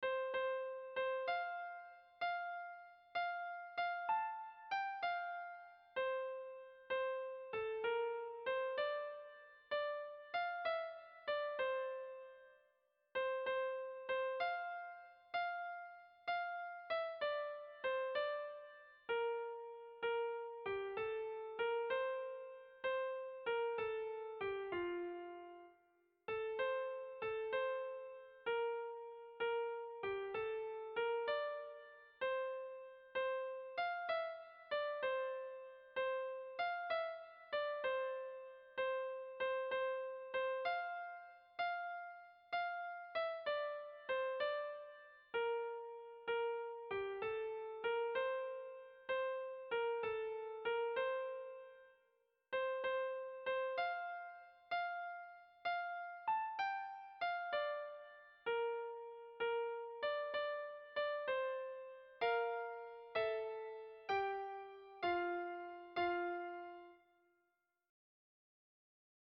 Hamarreko handia (hg) / Bost puntuko handia (ip)
A1A2BA2A3